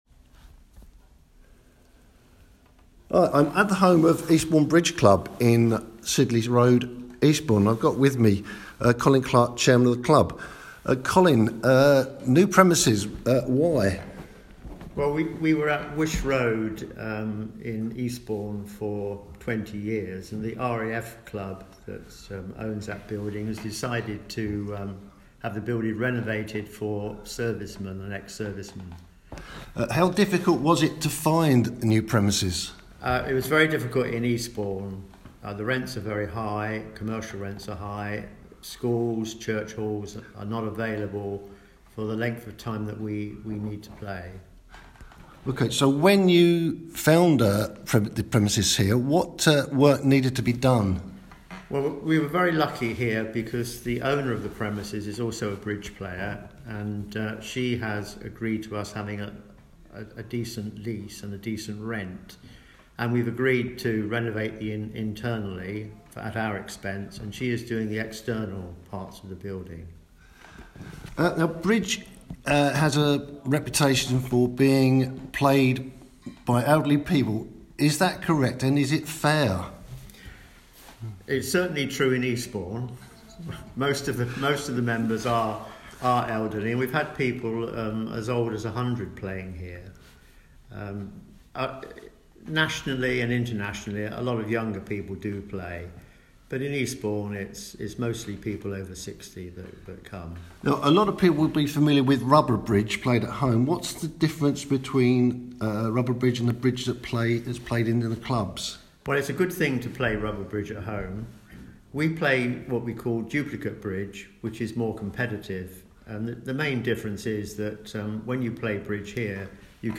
Radio_Interview.wav